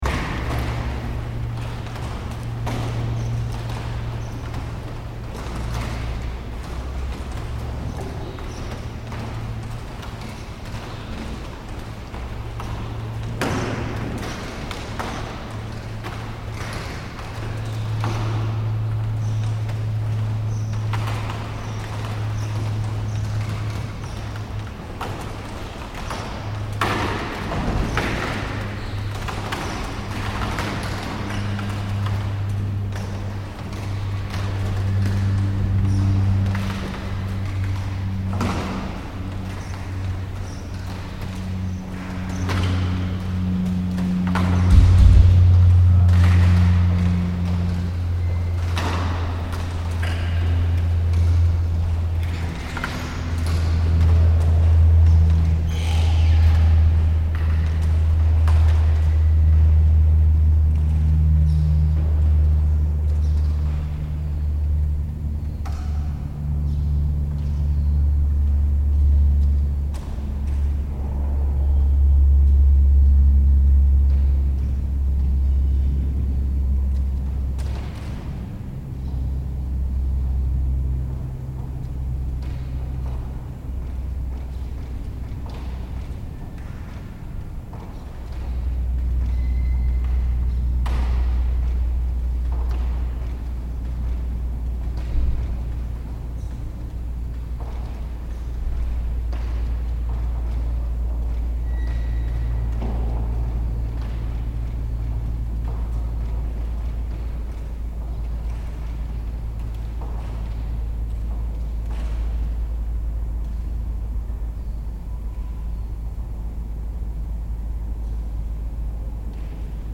This recording was a part of the site specific installation )) archi_teXtures sonores ((, which took place in L’Usine - CNAREP - Tournefeuille - France in September 2018.
Architecture considered here as a membrane: a listening medium from the inside to the outside and conversely… I particularly love this recording, firstly full of strength and energy powered by the wind and the traffic in the neighborhood, then back to a more quiet state that makes us discover the inside, the big volume of air, the relative emptiness of the space, and finally its inhabitants. Recorded with a couple of Sennheiser 8020 (AB)s in a SD mixpre6.